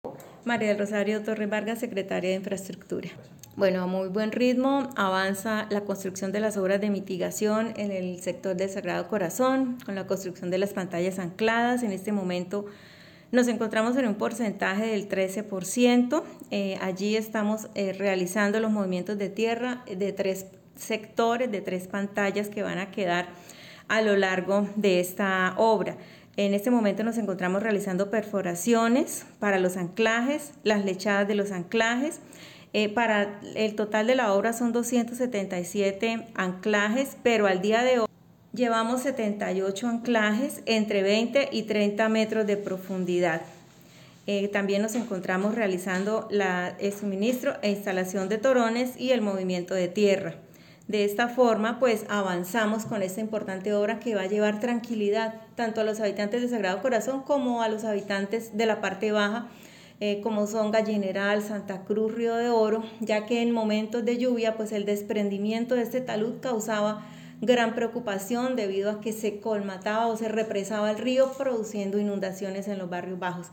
María del Rosario Torres, Secretaria de Infraestructura Girón.mp3